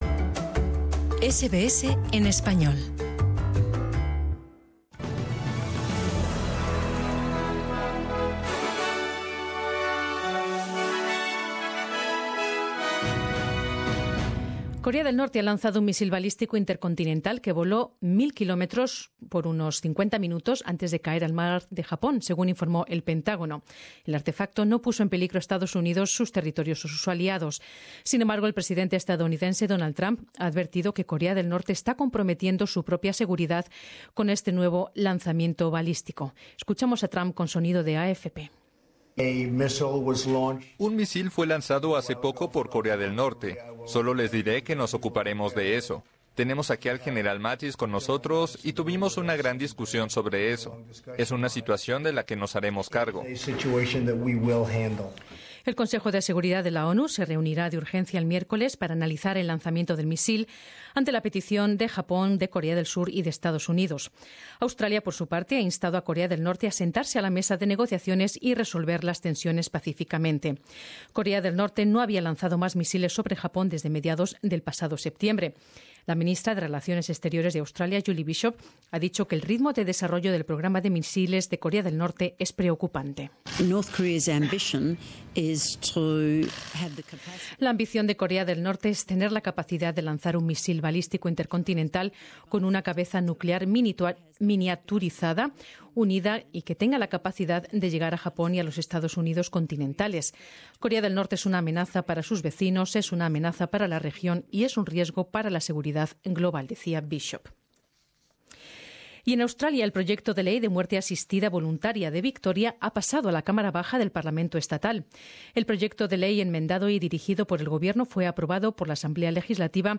Extracto del boletín de noticias de SBS en español